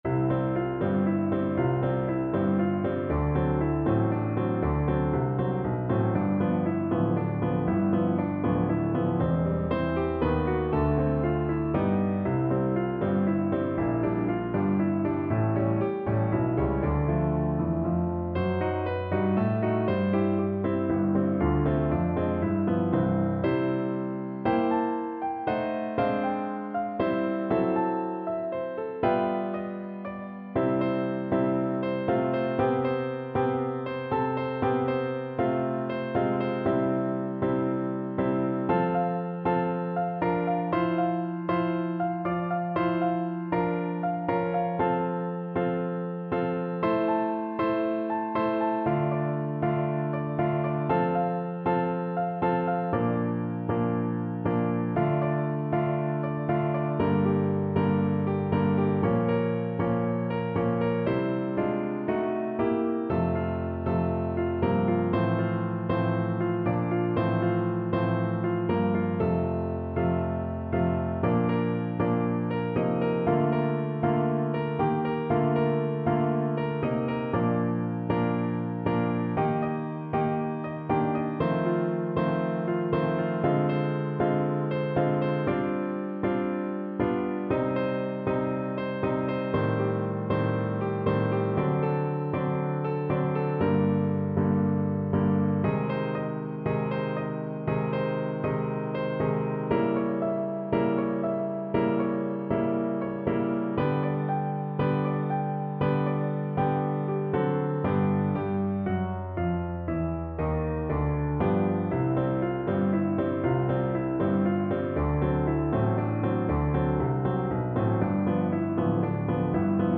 No parts available for this pieces as it is for solo piano.
3/4 (View more 3/4 Music)
Piano  (View more Intermediate Piano Music)
Classical (View more Classical Piano Music)